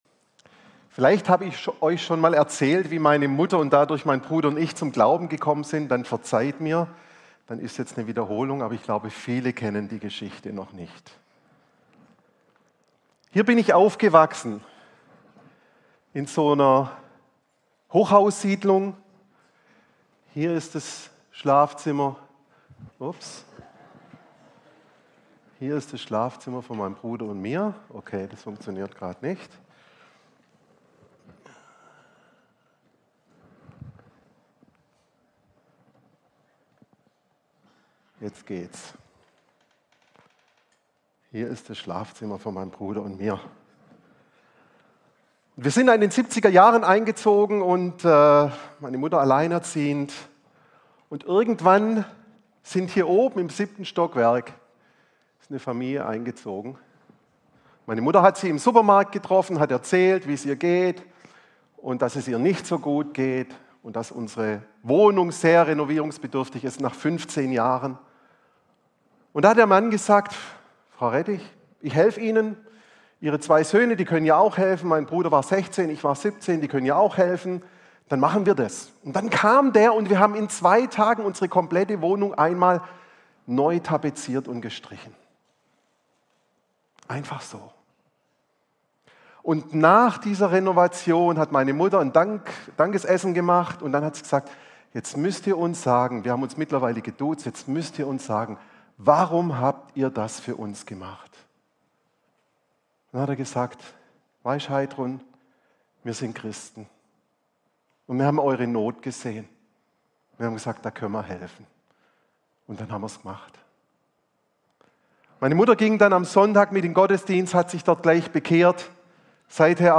Predigtserien